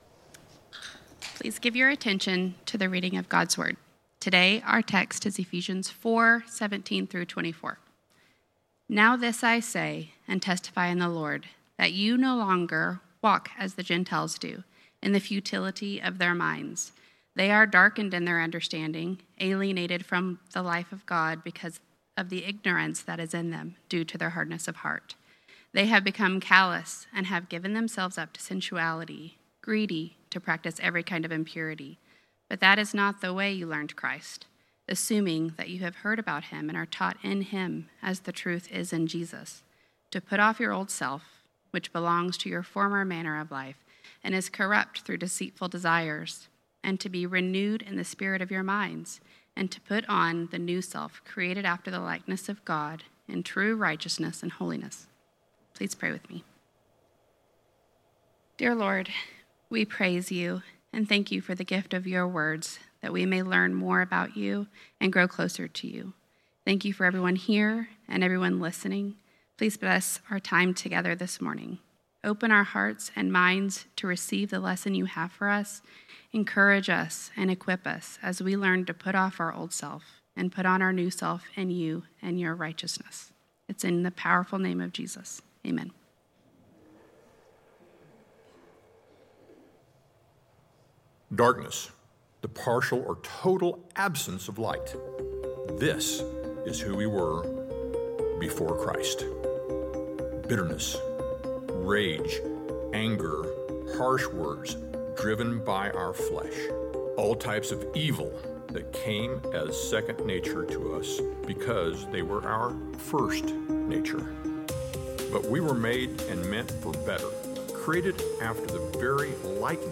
A Sermon All (or Mostly) About Clothes